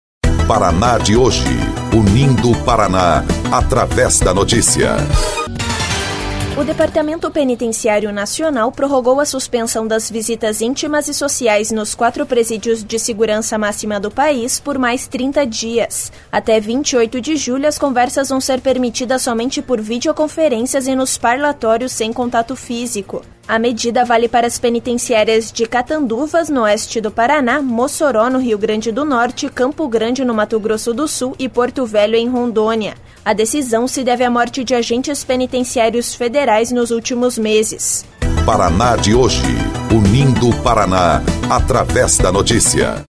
BOLETIM - Depen mantém suspensão de visitas em presídios federais